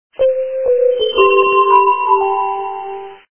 Campanas dulces de navidad en nuestro NUEVOS TONOS NAVIDEÑOS